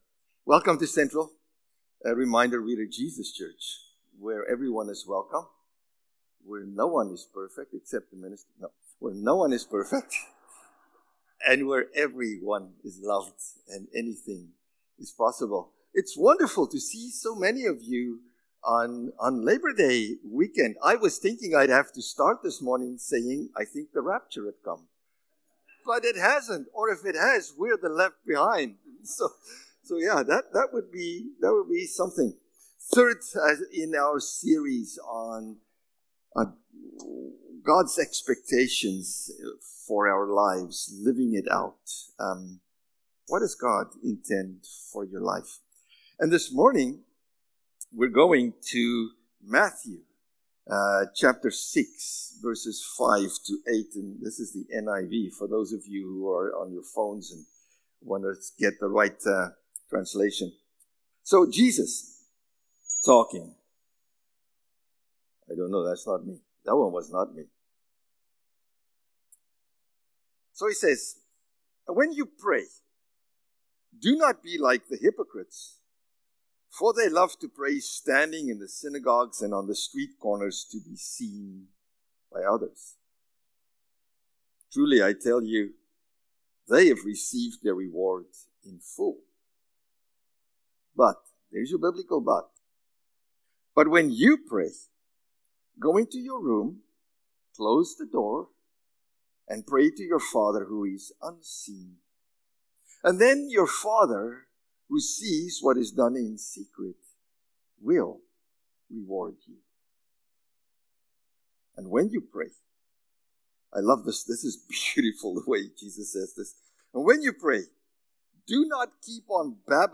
September-1-Sermon.mp3